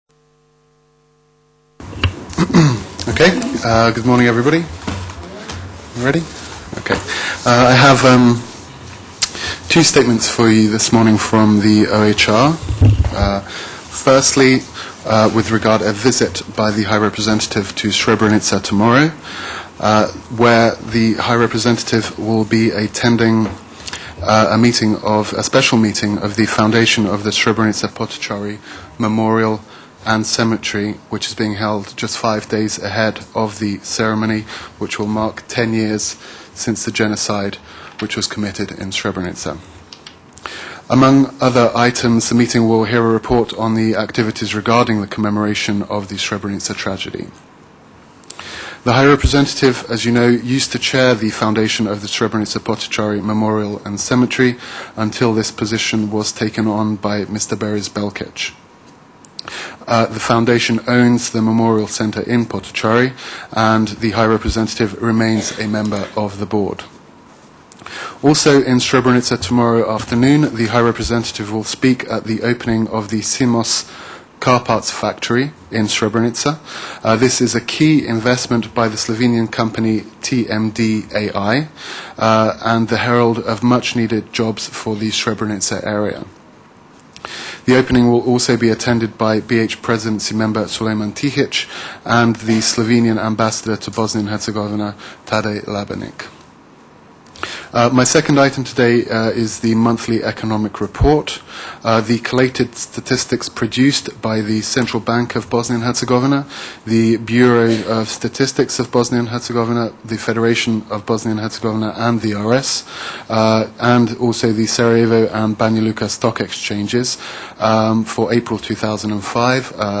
Transcript of the International Agencies’ Joint Press Conference